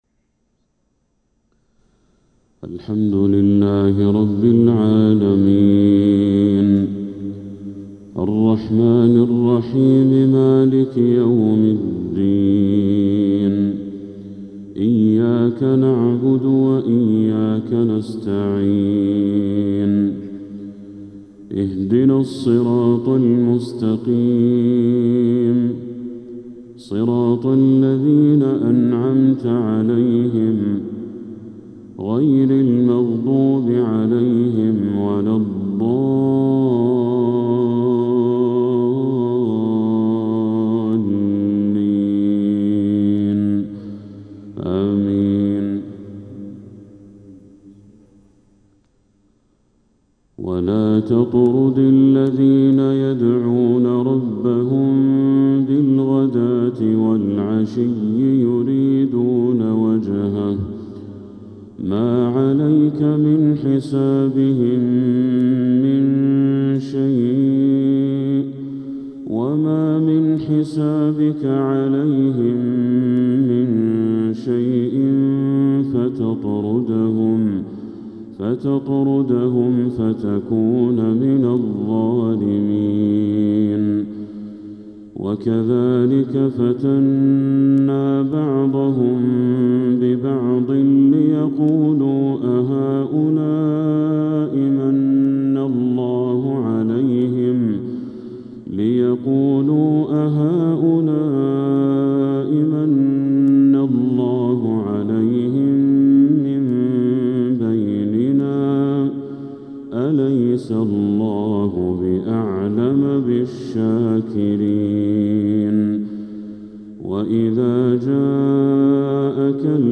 فجر السبت 10 محرم 1447هـ من سورة الأنعام 52-68 | Fajr prayer from Surat Al-An'aam 5-7-2025 > 1447 🕋 > الفروض - تلاوات الحرمين